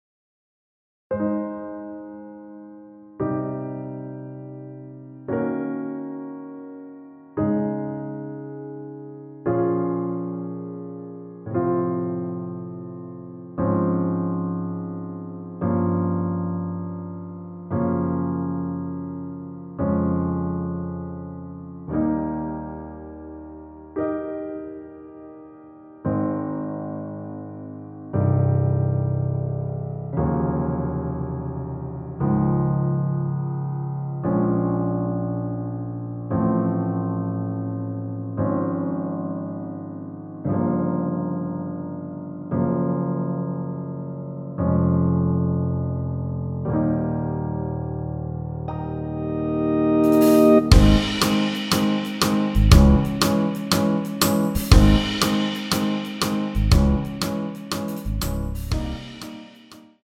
원키에서(-2)내린 MR입니다.
Ab
앞부분30초, 뒷부분30초씩 편집해서 올려 드리고 있습니다.
중간에 음이 끈어지고 다시 나오는 이유는